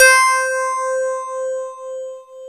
Index of /90_sSampleCDs/Club-50 - Foundations Roland/PNO_xFM Rhodes/PNO_xFM Rds C x2